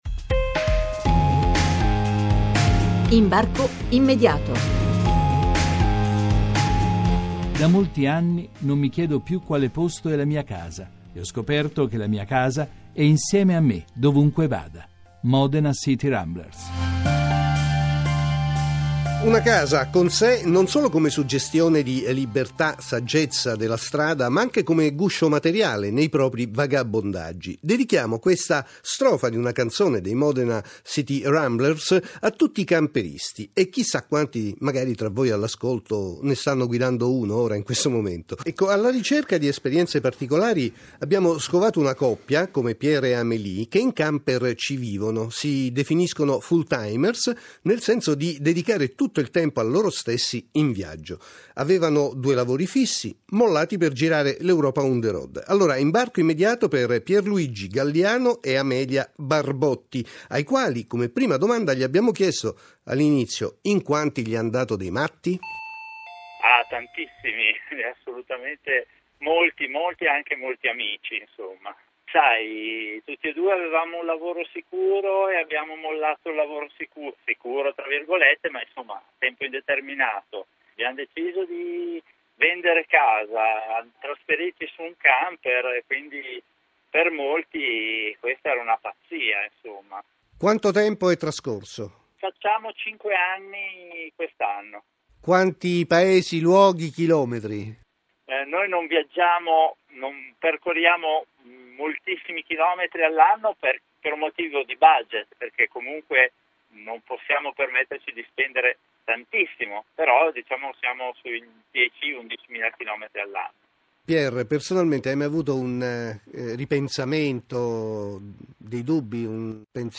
Interviste Radiofoniche